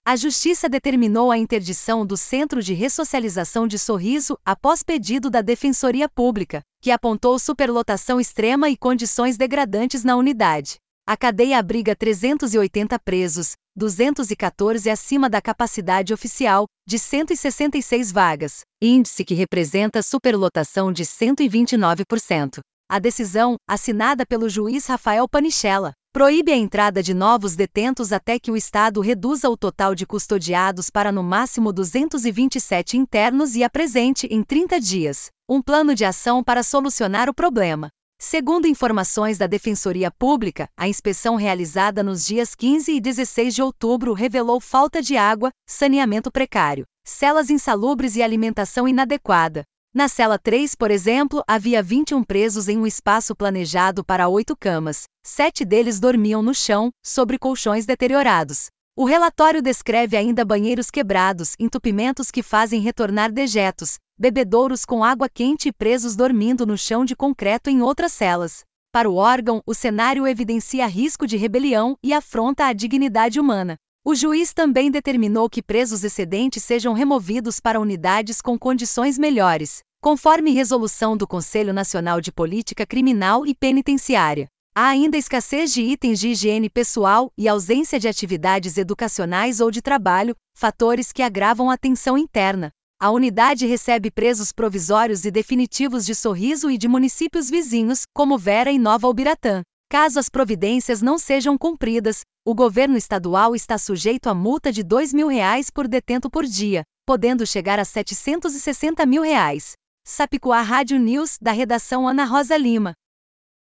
Boletins de MT 27 nov, 2025